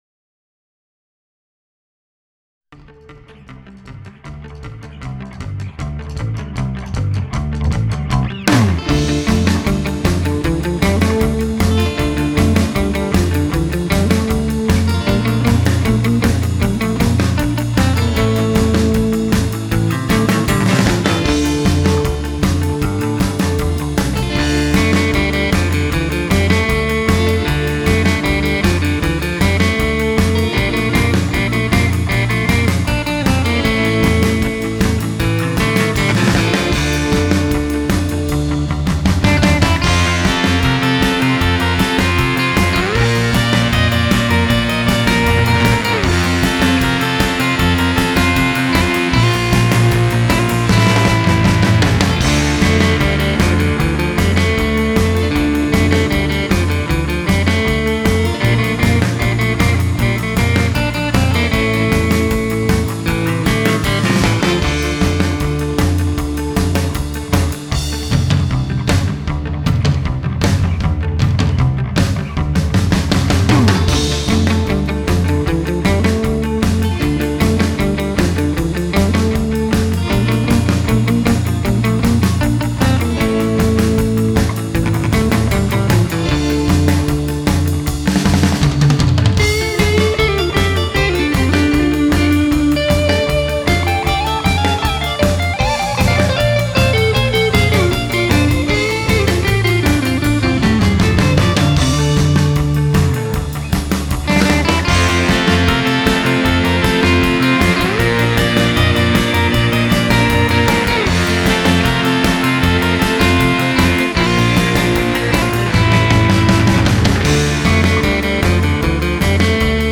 инструментального СЁРФ-РОКА
серф гитариста